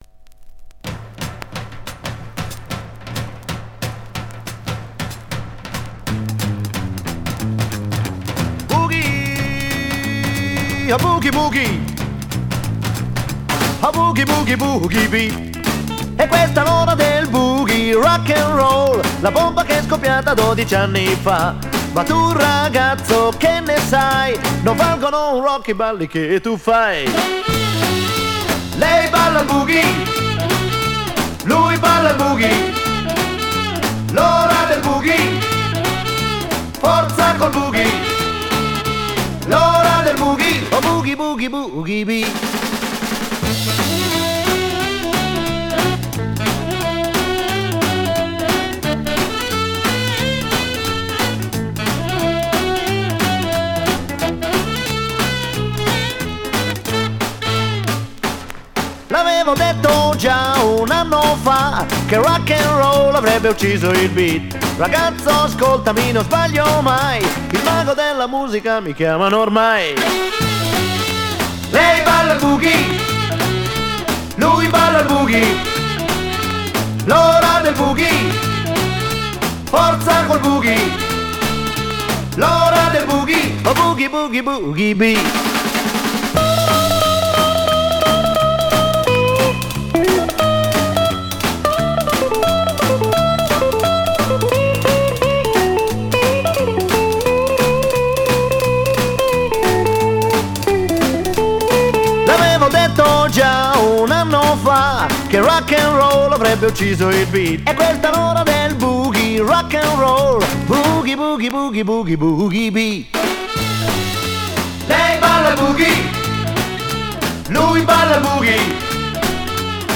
Жанр: Rock, Funk / Soul, Pop
Стиль: Rock & Roll, Chanson, Pop Rock, Ballad